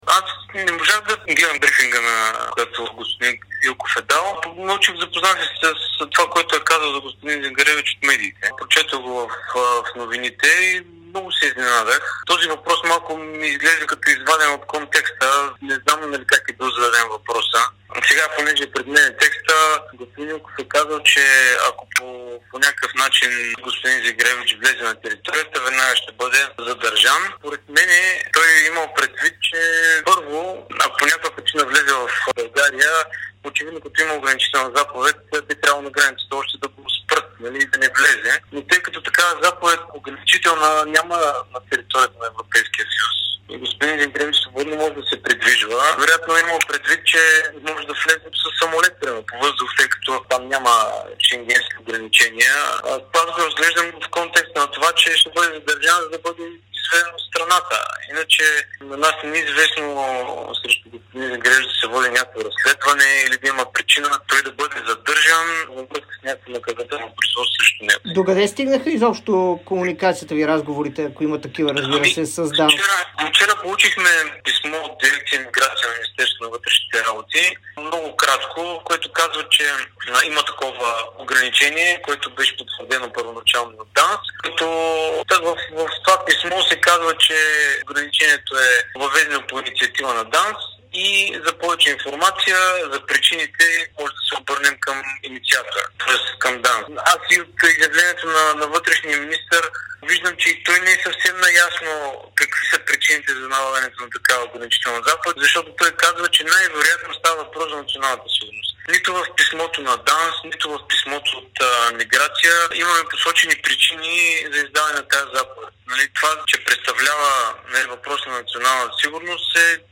говори ексклузивно пред Дарик радио и dsport